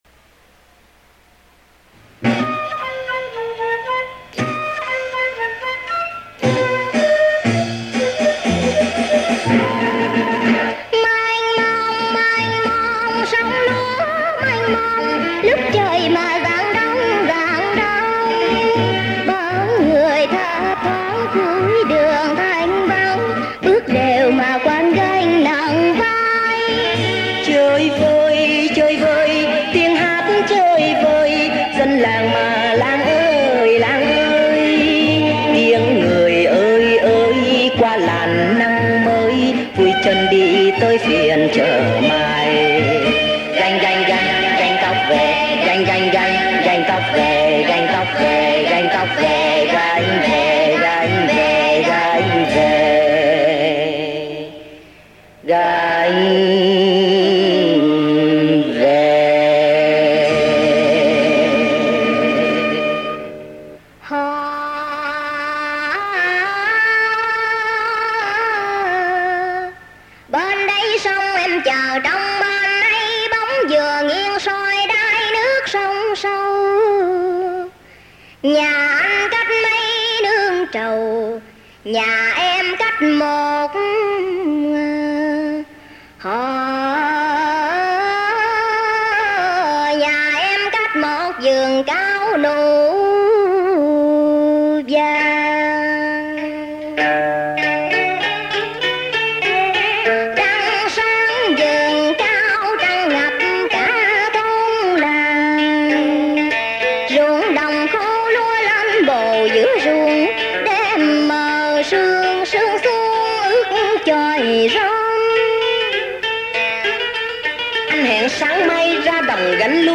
Thể loại: Tân cổ https